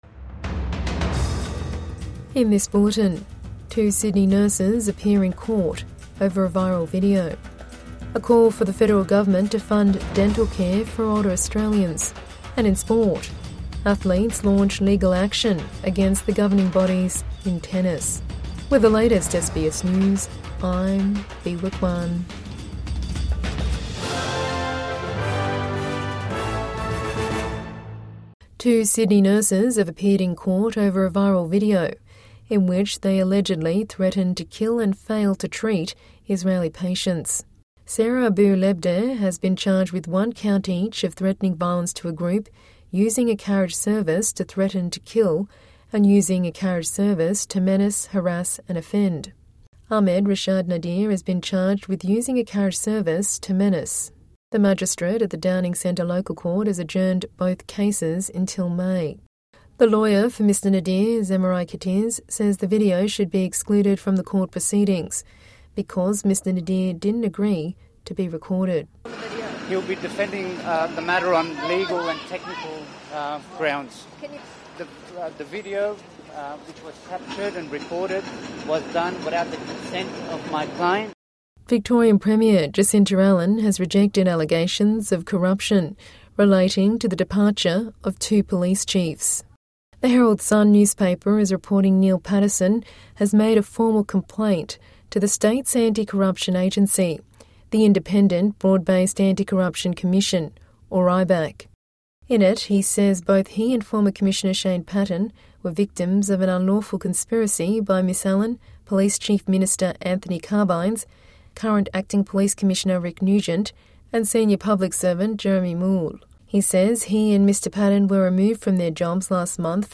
Evening News Bulletin 19 March 2025